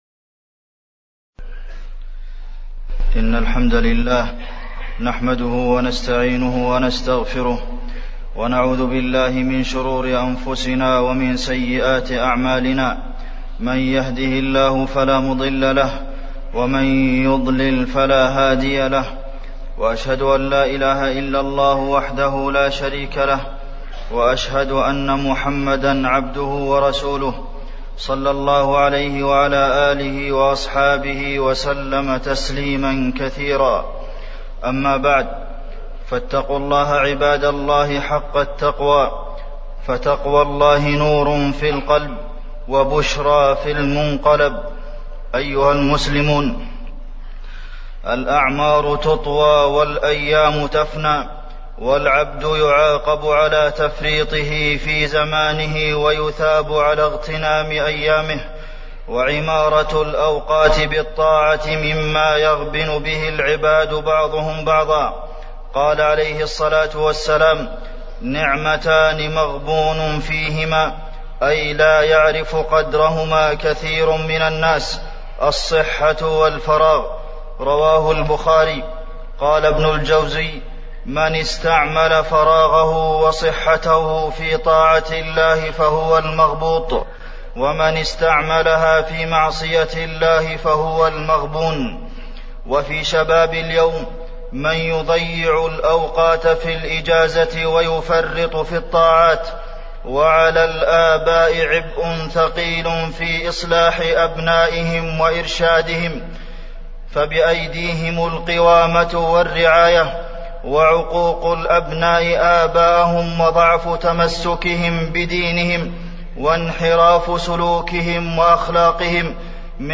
تاريخ النشر ٢ جمادى الآخرة ١٤٢٦ هـ المكان: المسجد النبوي الشيخ: فضيلة الشيخ د. عبدالمحسن بن محمد القاسم فضيلة الشيخ د. عبدالمحسن بن محمد القاسم استغلال الوقت